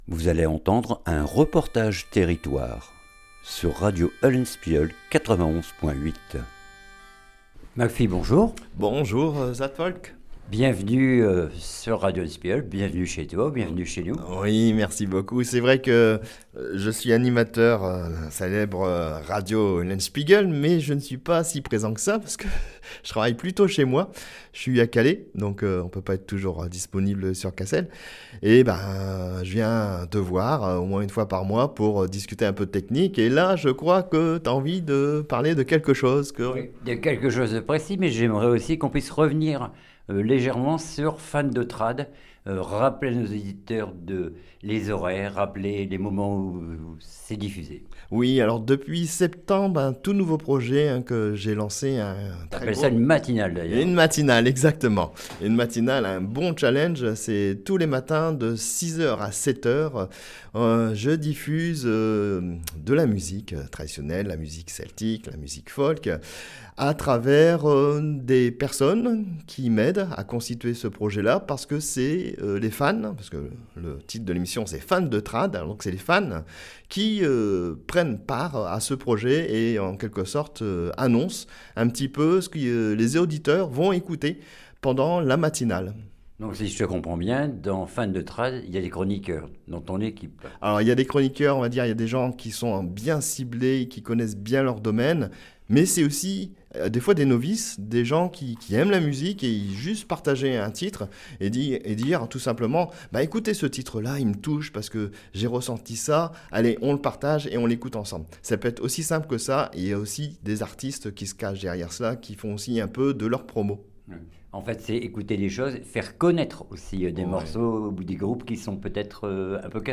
REPORTAGE TERRITOIRE SESSION FOLK OUVERTE AU HLF FESTIVAL